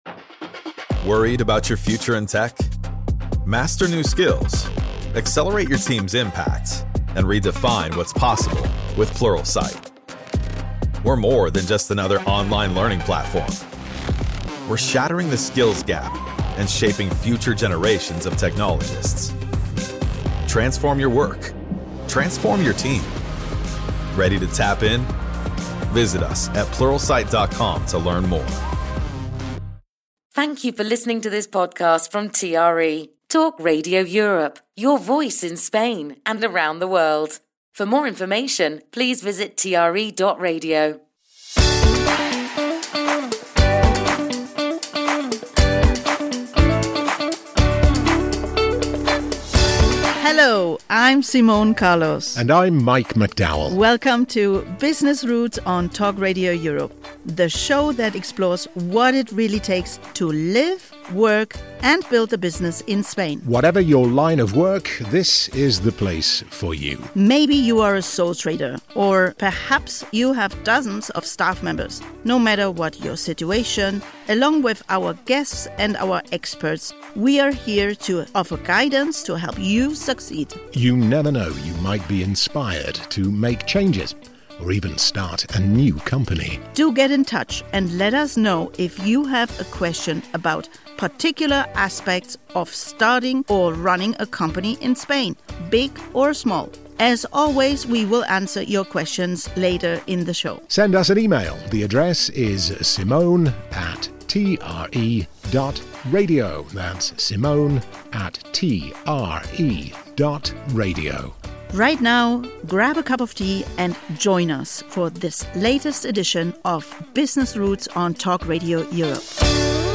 Tune into Business Routes where you'll hear inspirational stories from entrepreneurs who set up and run their own enterprises here in Spain.